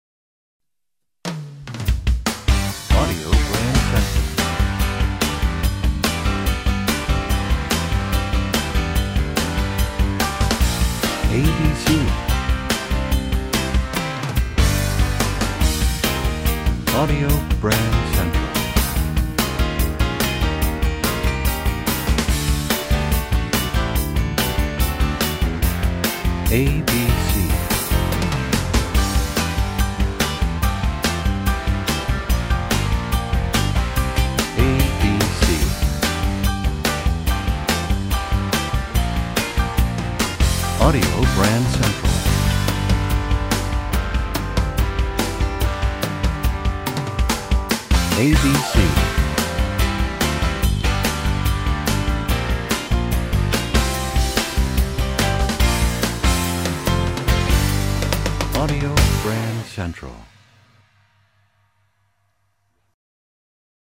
Genre: Theme Music.